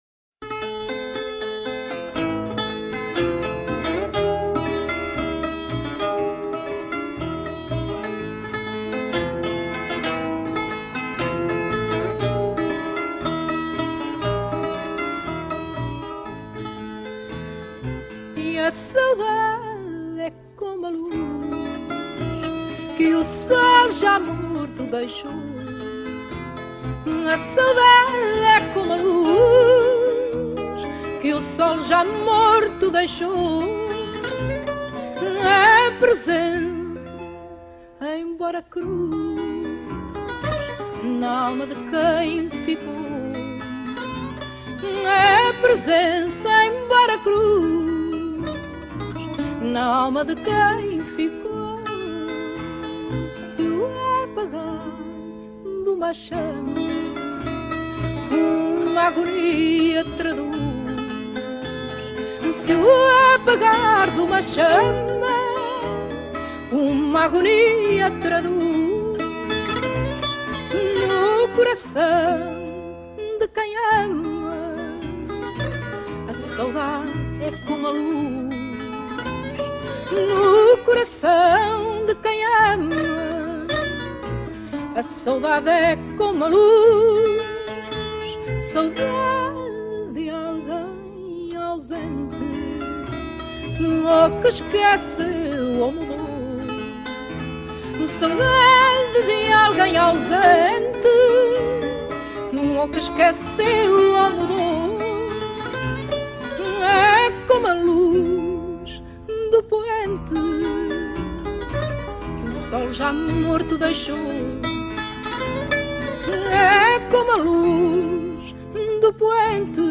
Музыка Планеты: музыка Португалии